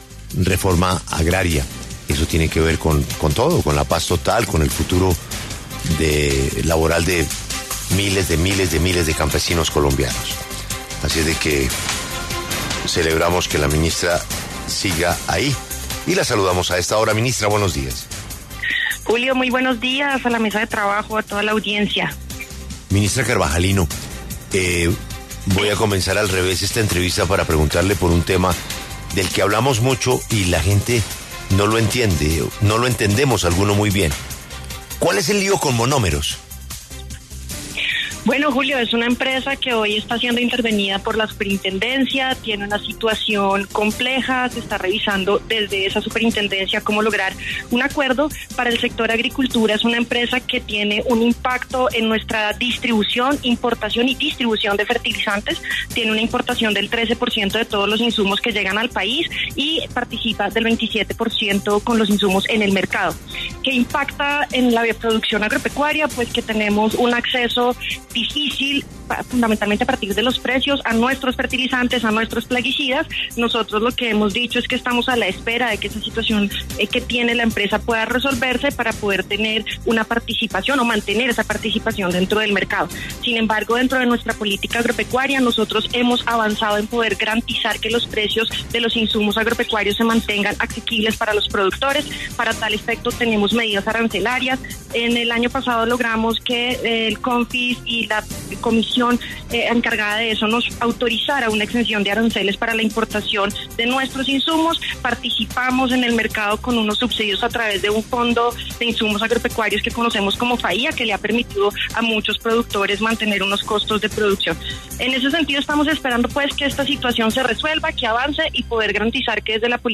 Ministra de Agricultura, Martha Carvajalino, habla en La W